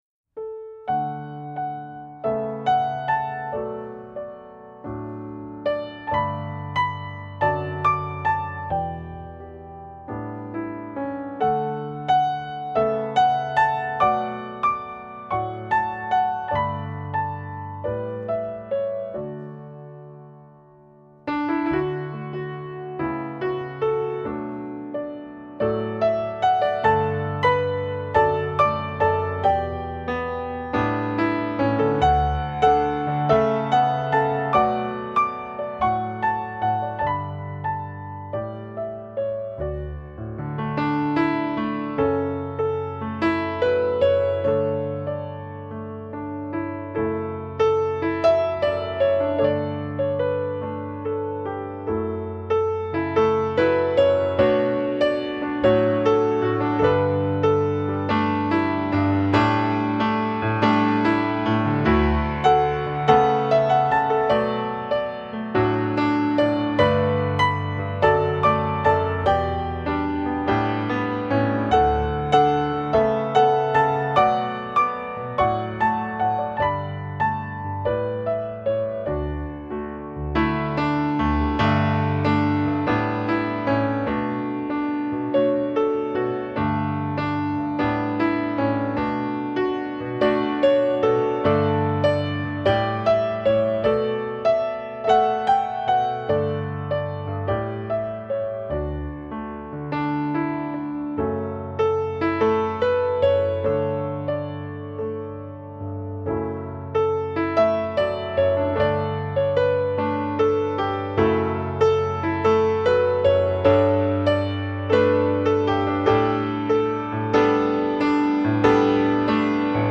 主要演奏器乐：钢琴
以恬美、宁静、隽永的琴声，
在延续以往的浪漫情韵外，更多了温暖人心的柔和色彩。